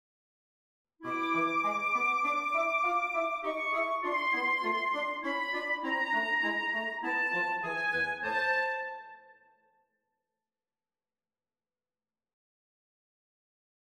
I have been working on a woodwind trio for which I have written a passage which has been confusing at best.